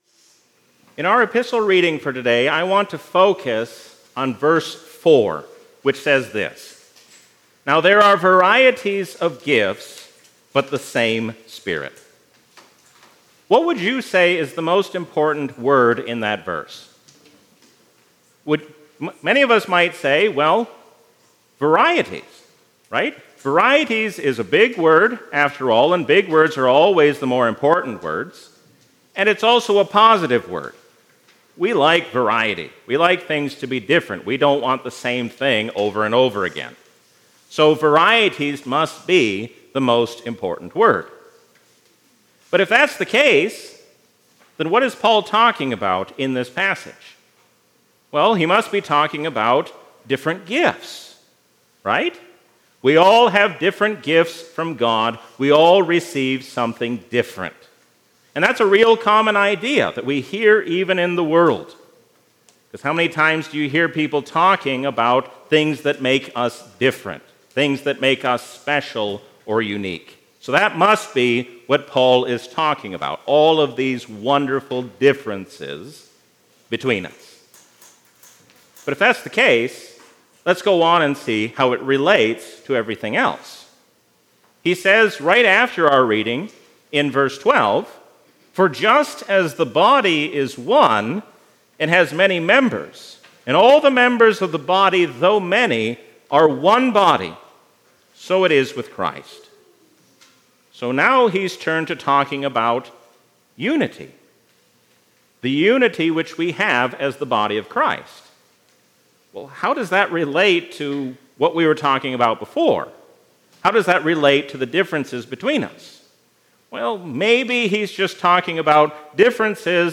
A sermon from the season "Trinity 2024." Let us seek to resolve our disputes in true unity and peace, because God has made us one in Jesus Christ.